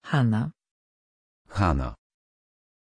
Pronunciation of Hana
pronunciation-hana-pl.mp3